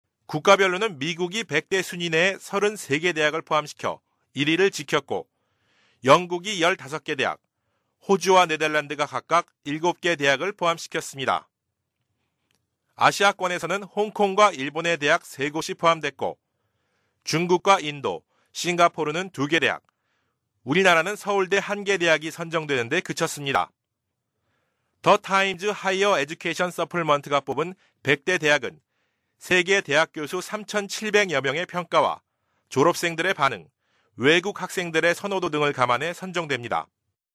Sprecher koreanisch für TV / Rundfunk / Industrie.
Kein Dialekt
Sprechprobe: Industrie (Muttersprache):